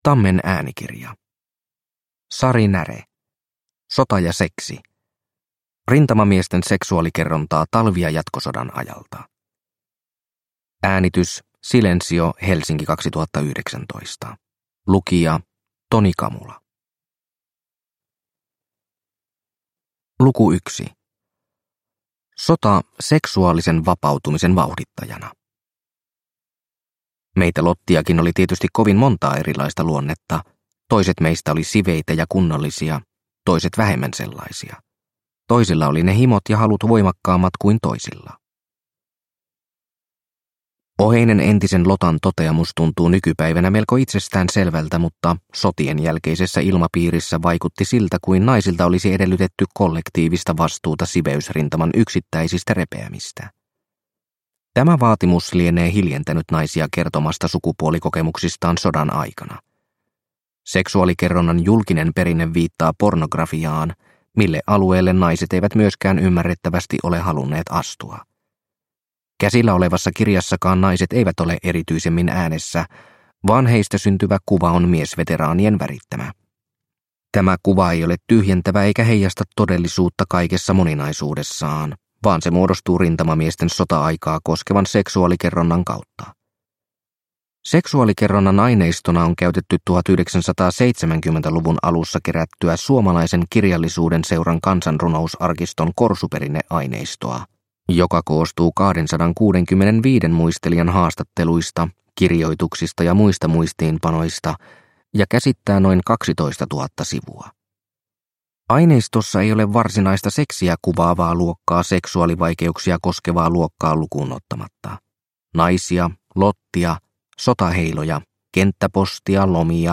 Sota ja seksi – Ljudbok – Laddas ner